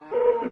mob / cow / hurt3.ogg
hurt3.ogg